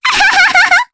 Luna-Vox_Happy3_jp.wav